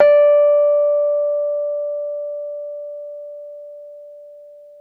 RHODES CL0FL.wav